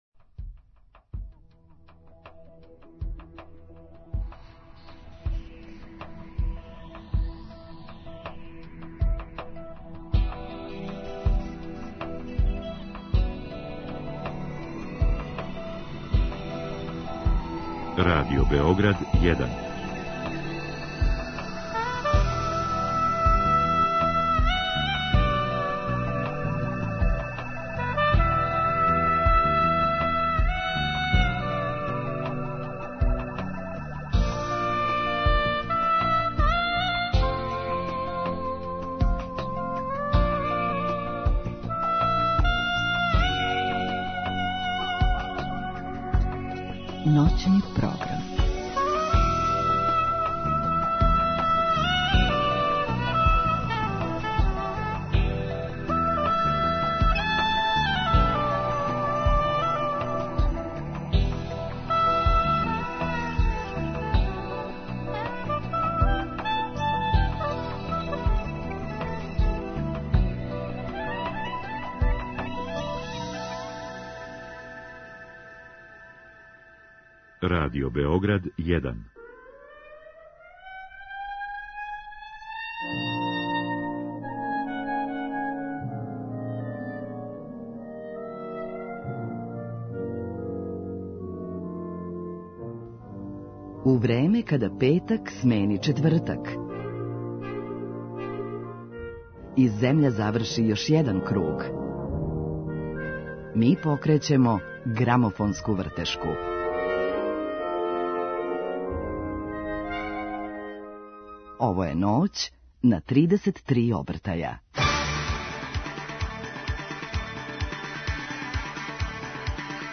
Гост Срђан Марјановић
У Ноћном програму имаћемо прилике да премијерно преслушамо песме са новог албума и сазнамо како су настајале.